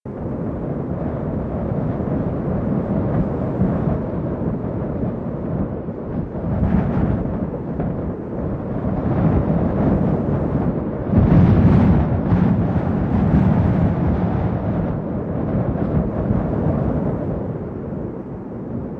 BaBoOn-R44-Wind.wav